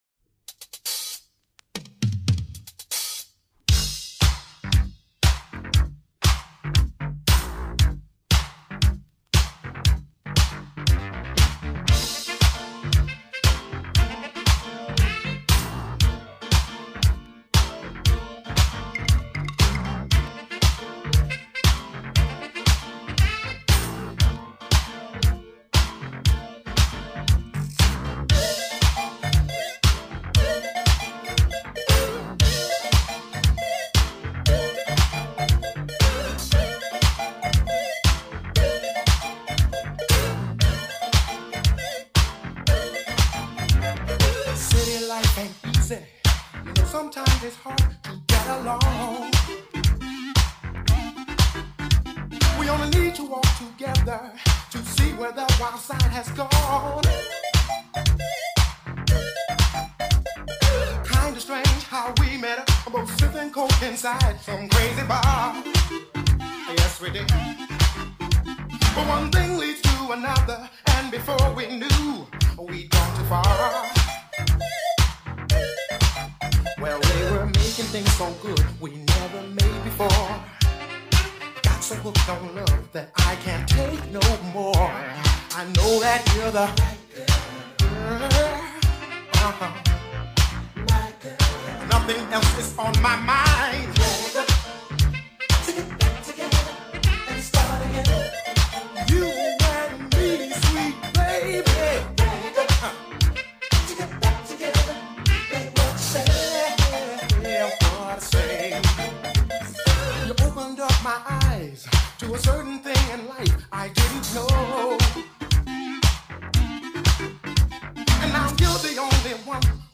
Chanson de style Disco-Funk sortie en 1983